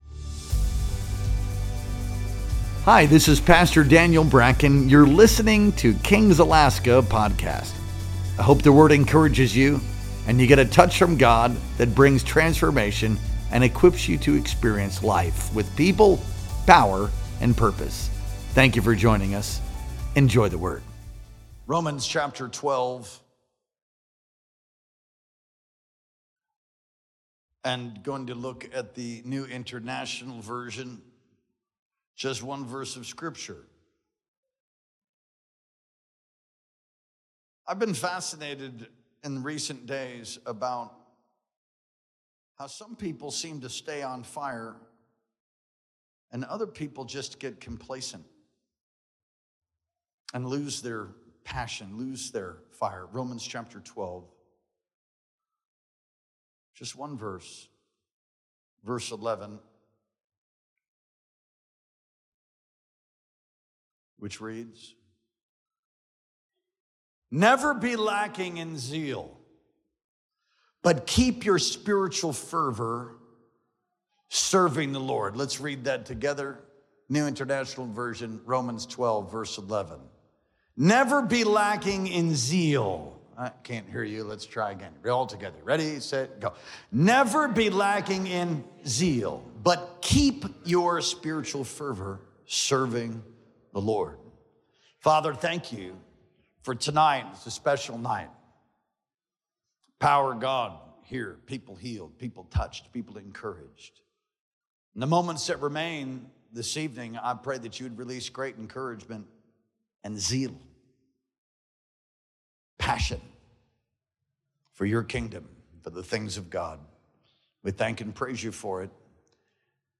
Our Sunday Night Worship Experience streamed live on July 13th, 2025.